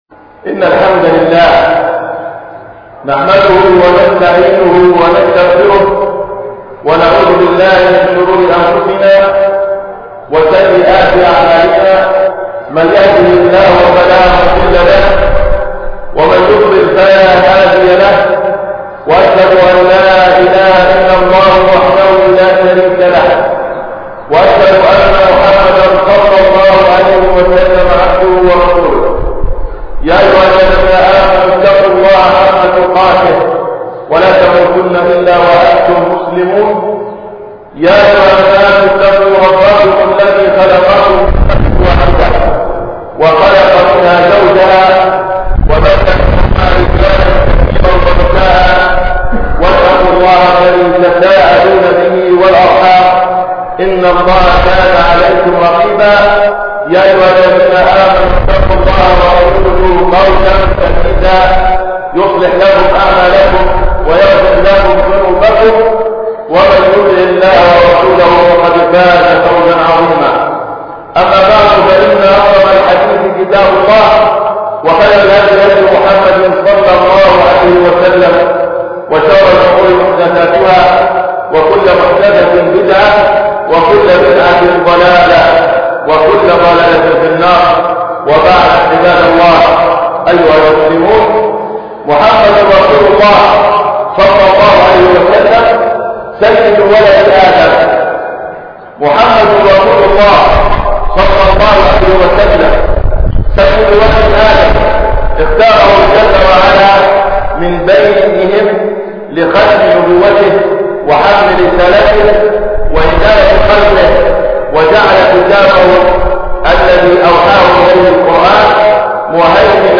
خطــبة الجمــعة (محمد رسول الله-صلى الله عليه وسلم-سيد ولد آدم )
بمســجد الحـــمد – خان يونس